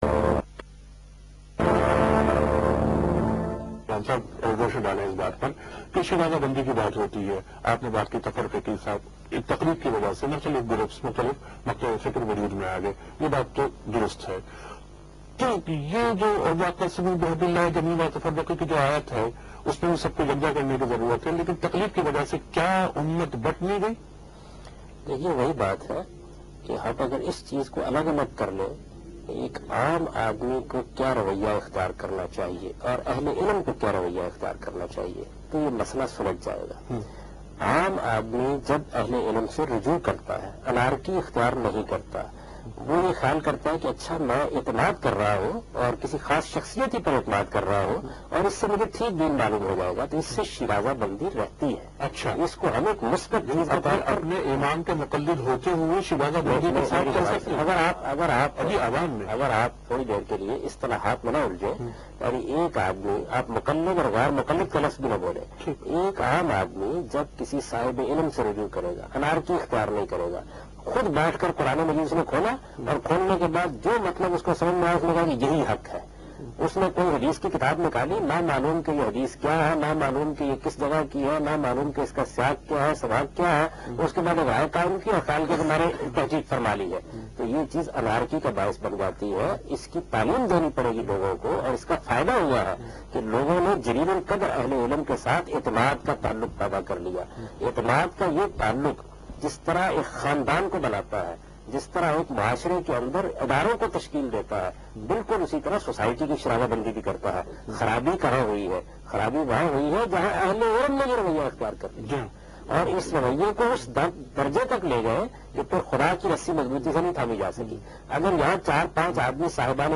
Category: TV Programs / Geo Tv / Alif /
What is Taqleed (Imitation)? Is Taqleed is obligatory ? This Program was telecast on Geo TV